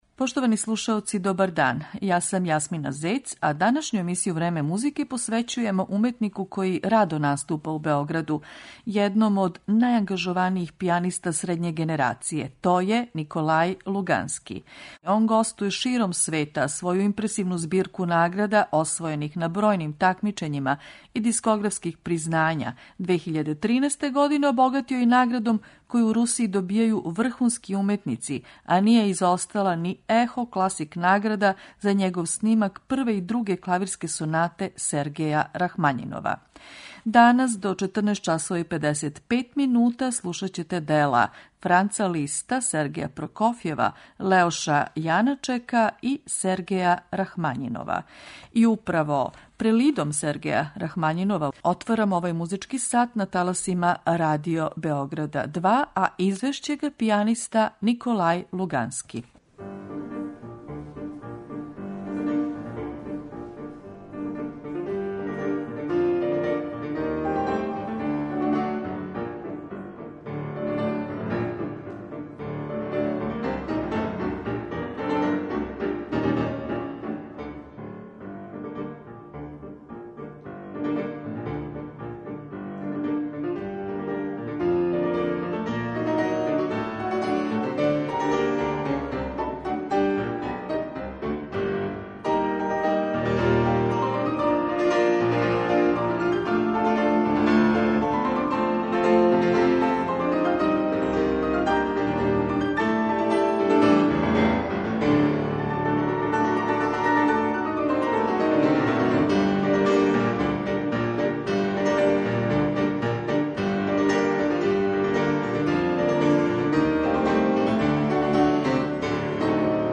Емисију посвећујемо славном руском пијанисти Николају Луганском, кога је у више наврата имала задовољство да слуша и београдска публика.